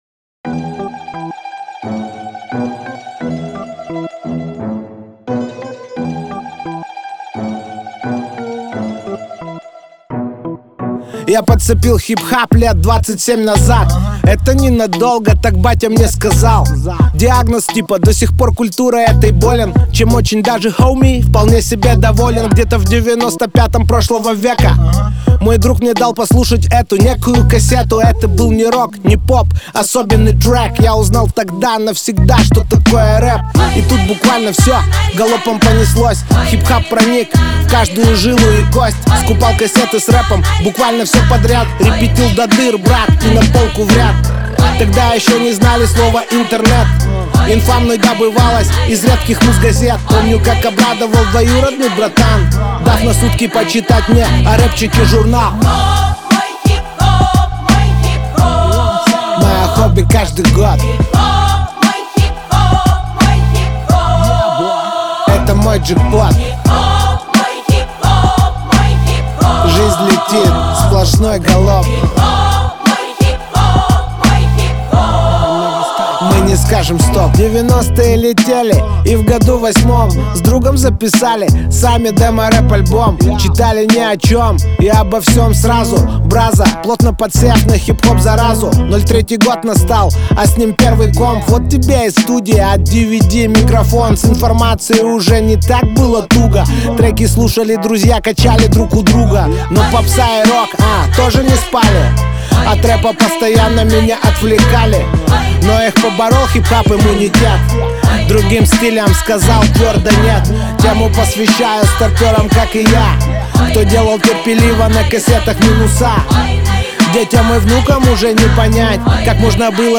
Драм-н-басс